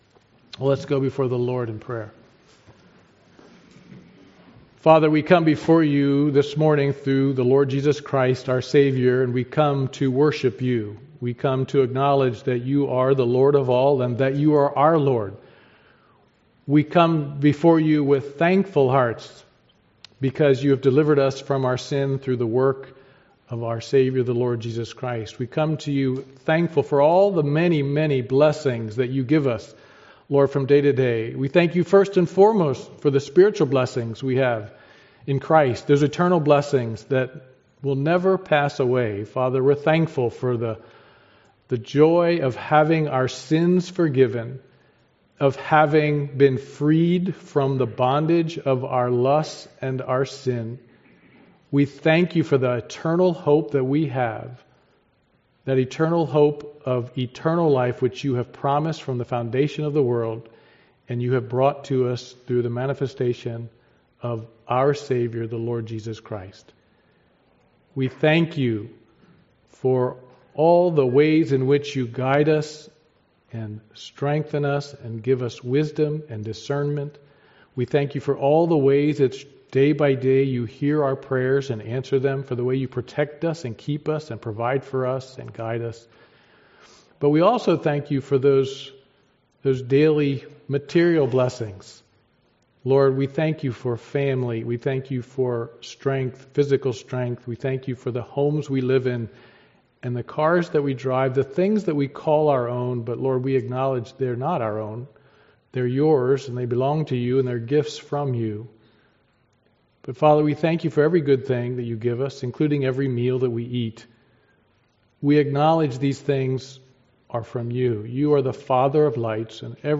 Passage: Ephesians 5 Service Type: Sunday Morning Worship